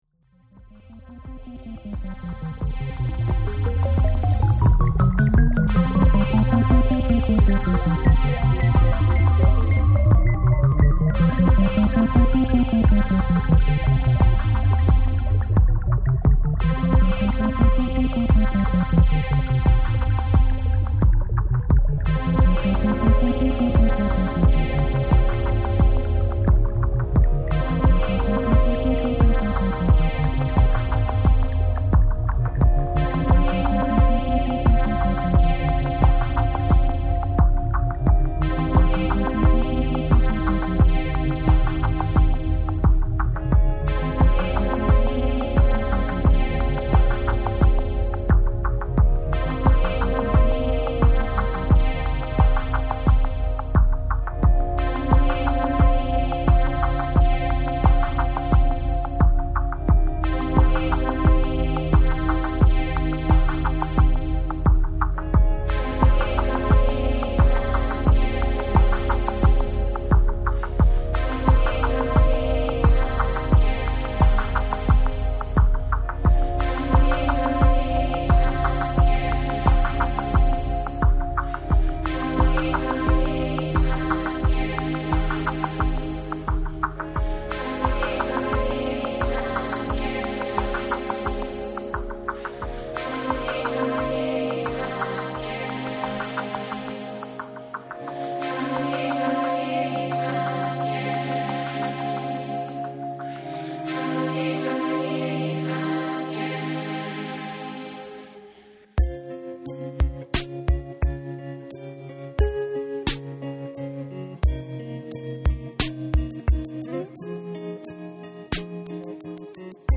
また、この曲は9分8秒にわたるゆったりとしたダンストラックと捉えることもできるだろう。
アフリカ的なパーカッションで始まり、途中では親指ピアノなどを挟みつつ、ふたたび印象的なあの合唱へと戻る。